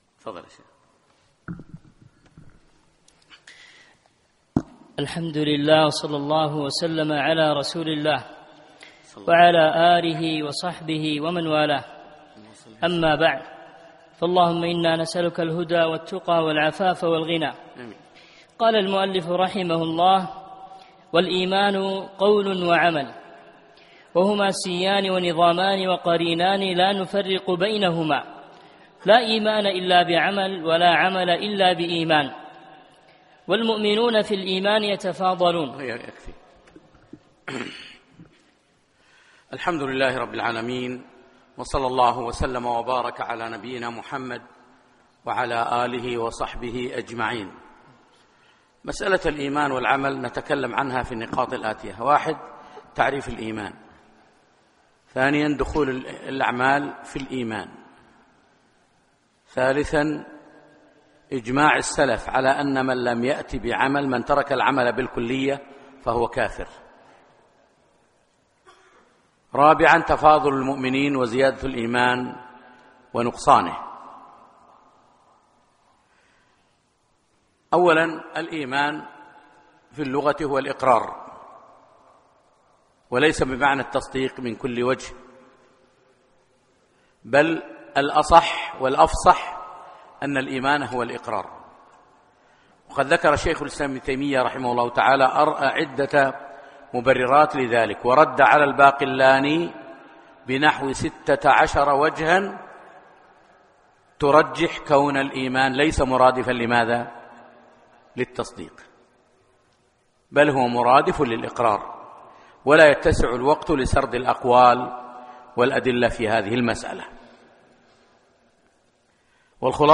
الأحد 2 4 2017 بعد صلاة العشاء مسجد صالح الكندري صباح السالم